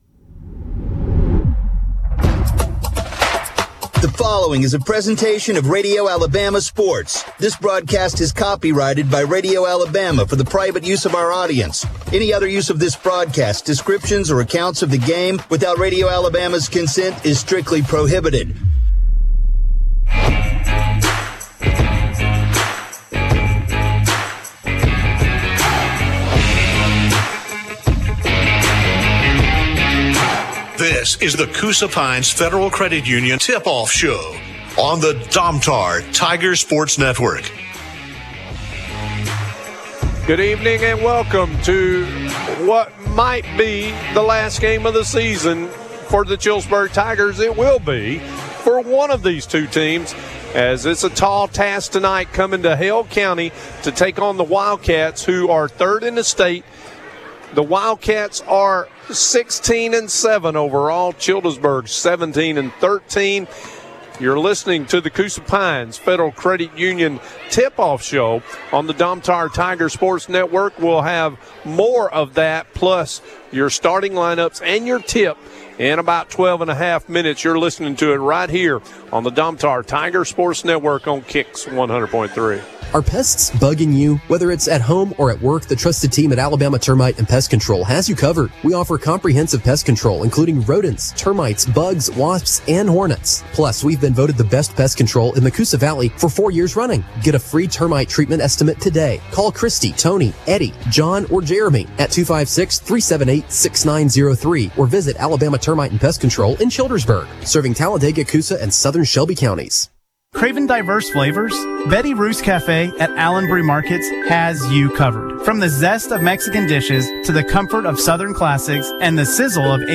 call Childersburg's game against Hale County in the State Tournament Sub-Regional. The Tigers lost 75-43.